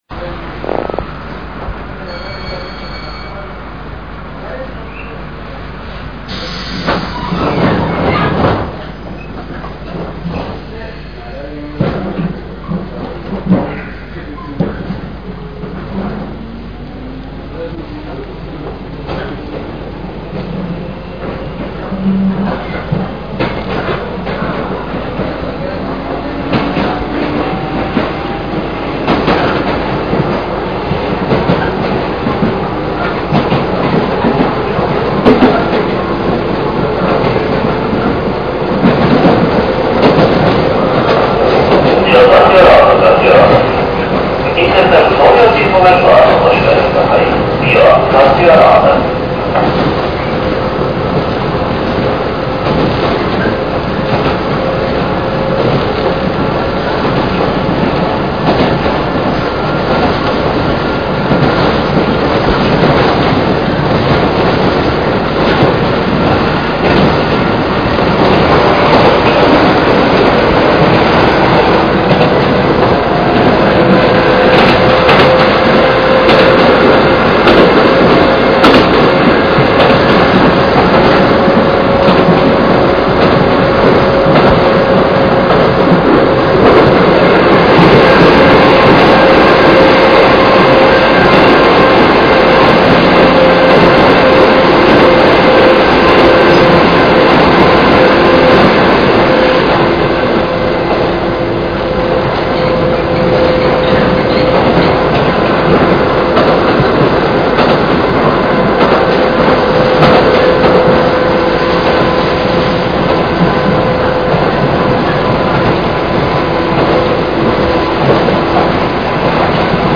MP−３ファイルにしたので駅間すべて収録されていますが、元テープは古いもので、録音技術も未熟なため音質は悪いかもしれません。
新性能国電
４　クモハ１０１（西ムコ）　通称・相模湖臨　相模湖・高尾間　　弱メ界磁を使用しない山越え運転　　　　　　10、41秒　　３.０５ＭＢ（４０ｋｂｐｓ）